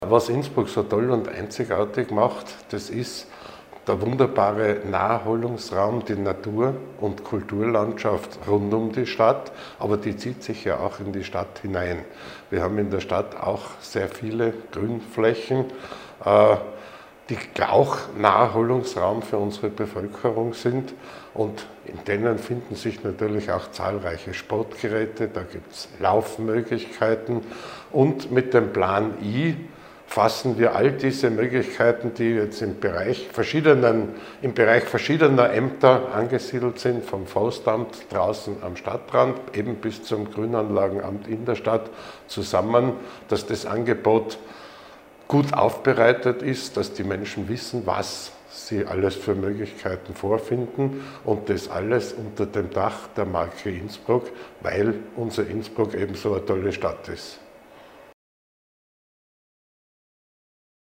O-Ton von Stadtrat Franz X. Gruber